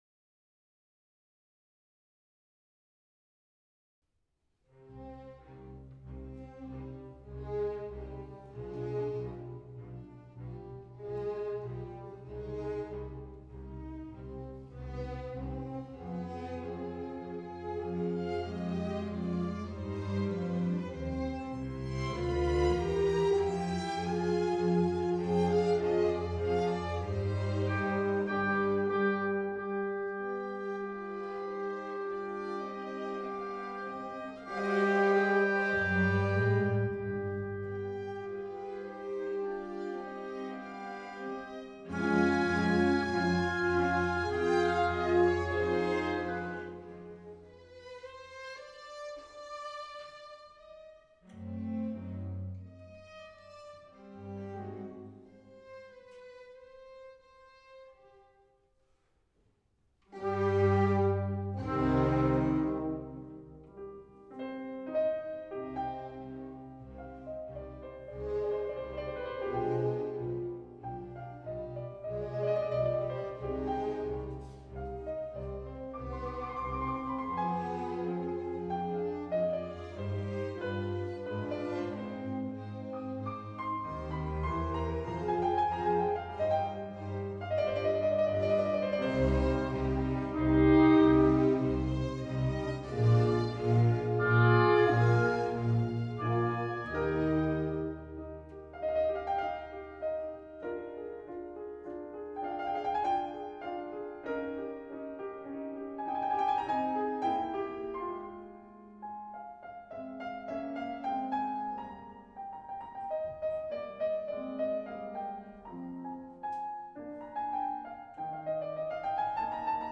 Brunnthaler Konzert vom 26. Dez. 2012:
W.A. Mozart - Konzert für Klavier Es-Dur KV 271